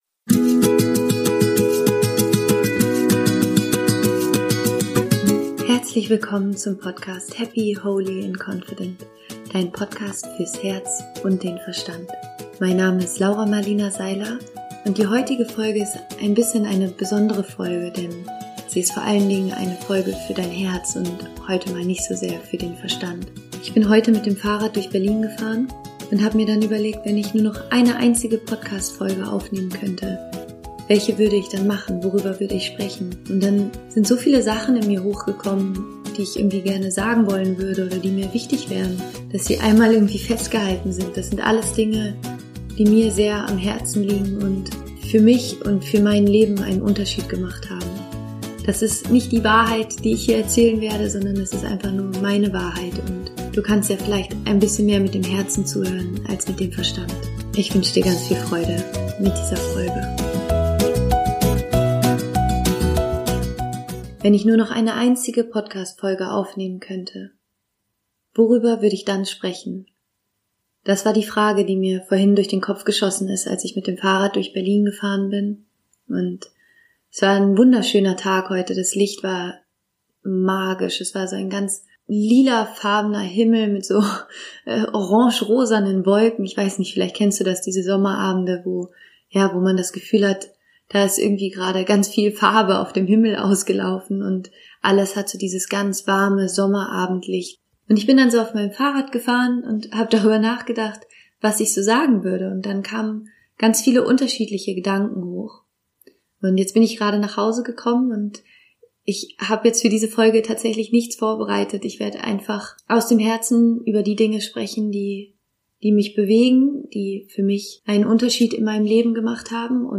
Sie ist komplett unvorbereitet aufgenommen und frei von Herzen gesprochen.